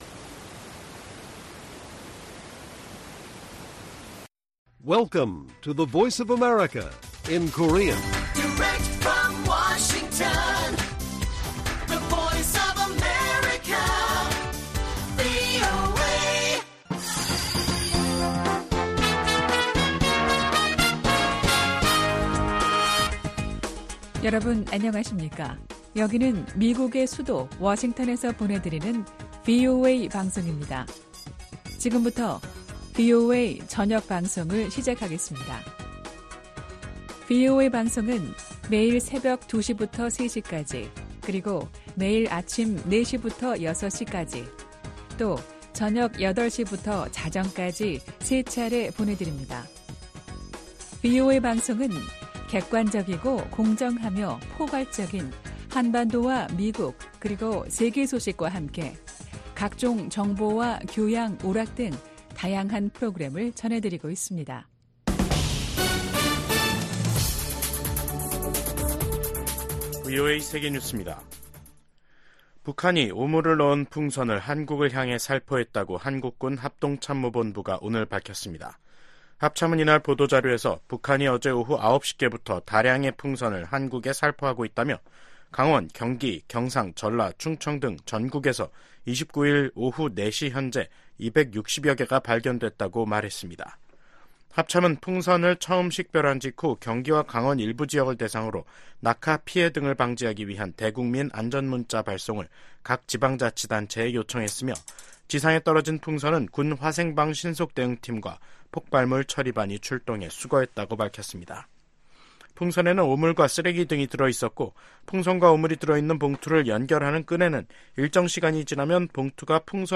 VOA 한국어 간판 뉴스 프로그램 '뉴스 투데이', 2024년 5월 29일 1부 방송입니다. 미 국무부는 최근의 한일중 정상회담과 관련해 북한 문제에 대한 중국의 역할이 중요하다는 점을 거듭 강조했습니다. 전 세계 주요국과 국제기구들이 계속되는 북한의 미사일 발사는 관련 안보리 결의에 대한 명백한 위반이라고 비판했습니다.